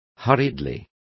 Complete with pronunciation of the translation of hurriedly.